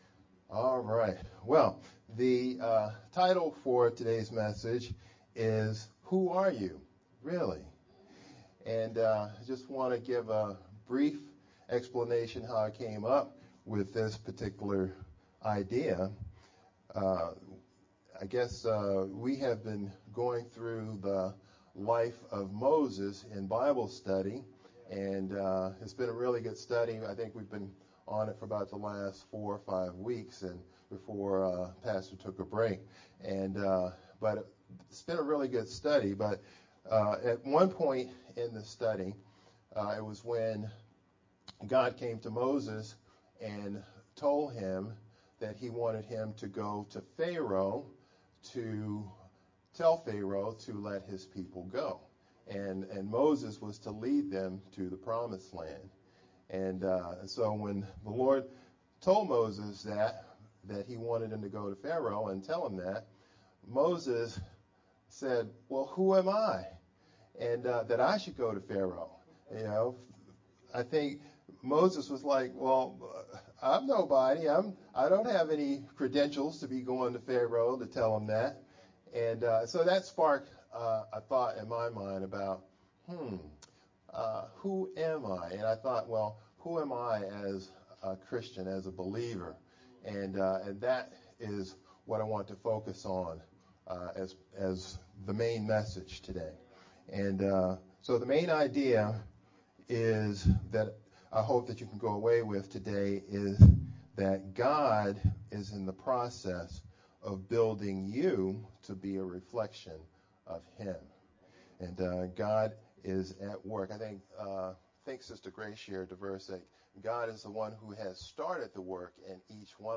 Aug-20th-Sermon-only-Made-with-Clipchamp_Converted-CD.mp3